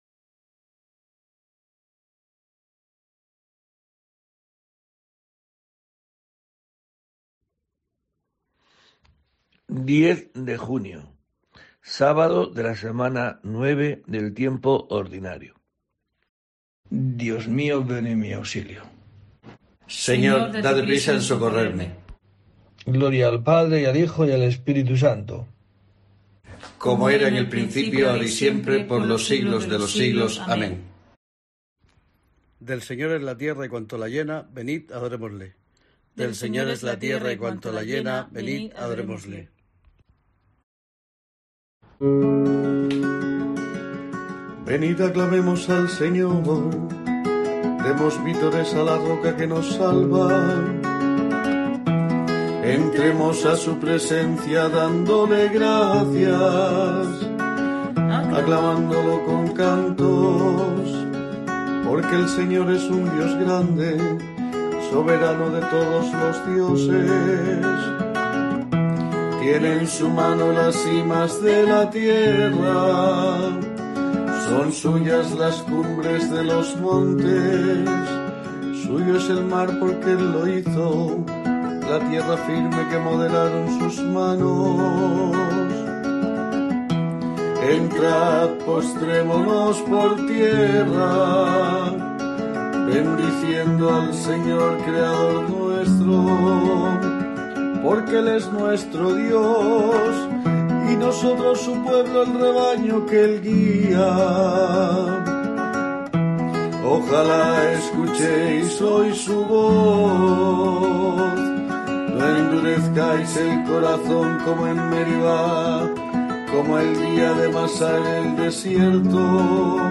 10 de junio: COPE te trae el rezo diario de los Laudes para acompañarte